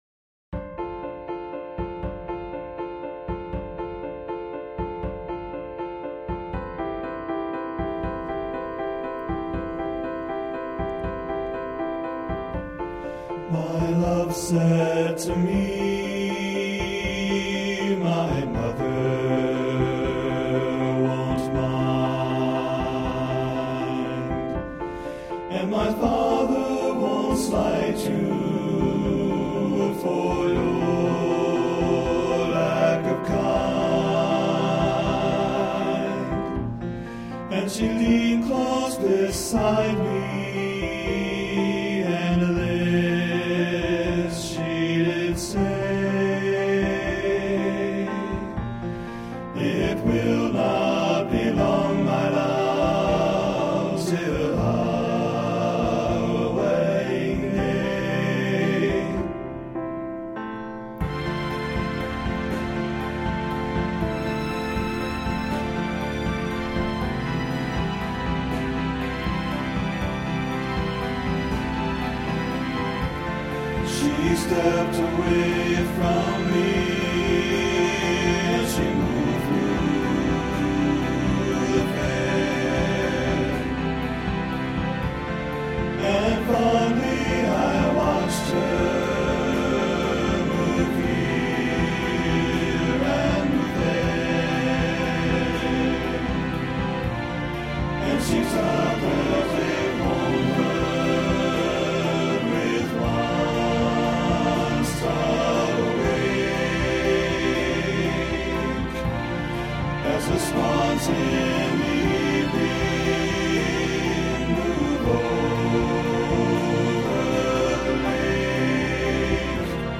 This arrangement is TTBB (2 div. per part) with a trio toward the middle.